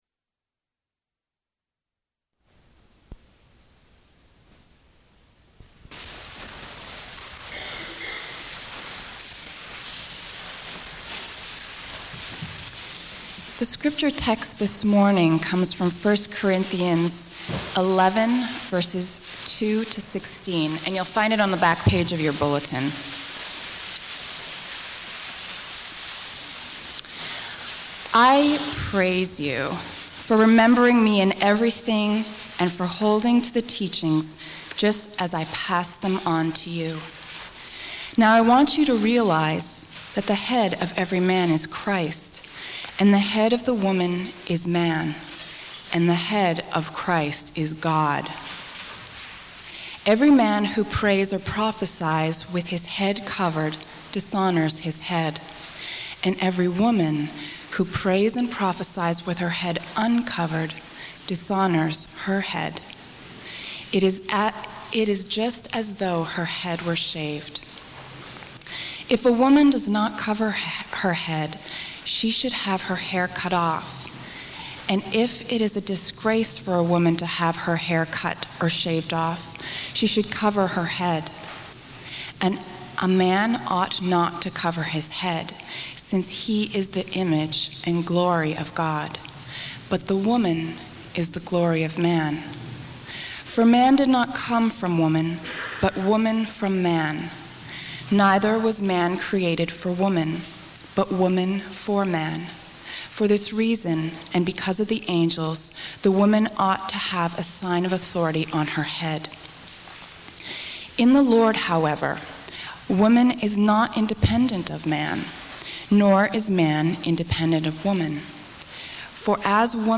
I begin to explore that truth here through Part 2 of this early exposition (03/13/2005) of 1Corinthians 11.